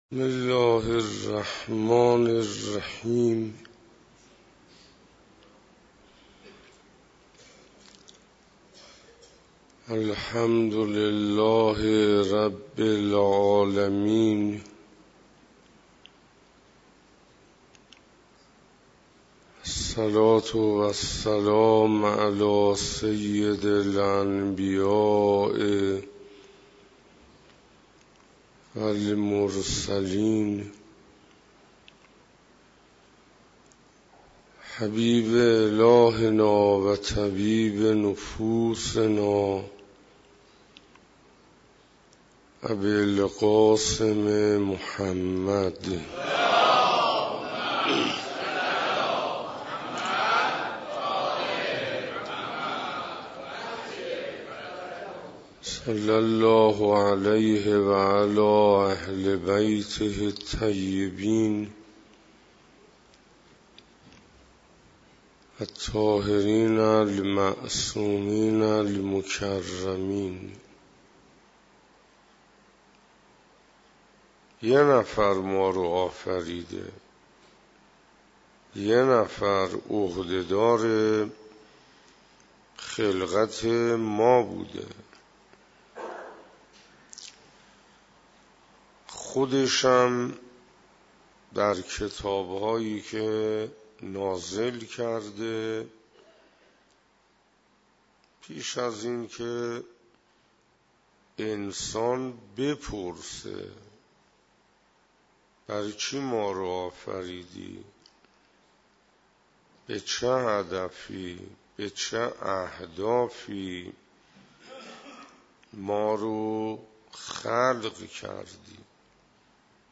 محرم97 - شب دوم - مسجد رسول اکرم(ص) - عبودیت(نماز)